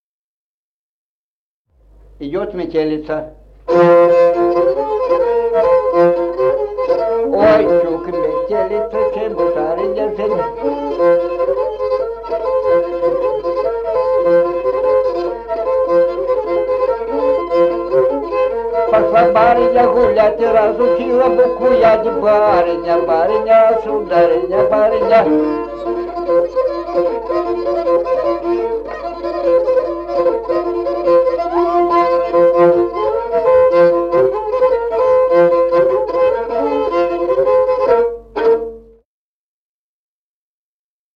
Музыкальный фольклор села Мишковка «Метелица», репертуар скрипача.